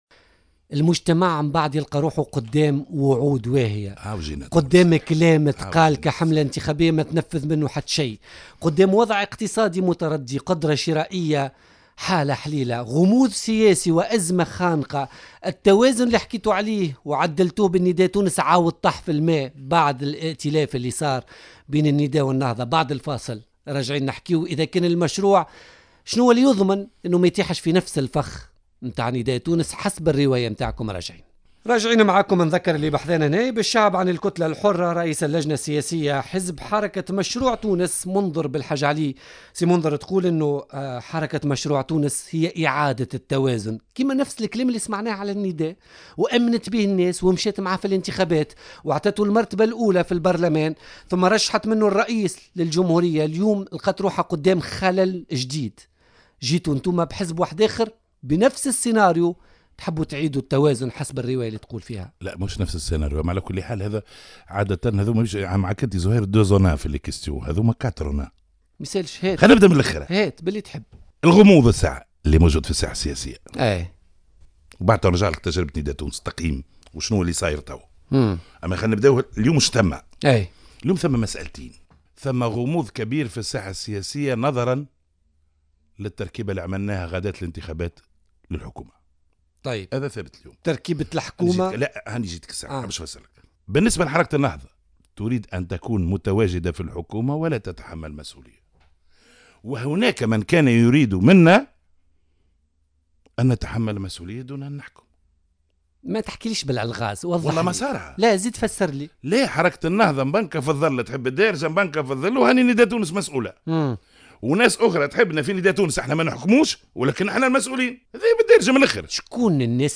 أكد رئيس اللجنة السياسية لحزب حركة مشروع تونس منذر بلحاج علي ضيف بوليتيكا اليوم الثلاثاء 10 ماي 2016 أن حركة النهضة تريد أن تكون موجودة في السلطة وتريد أن تحكم في الظل دون أن تتحمل المسؤولية .